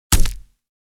Hit_Enemy3.wav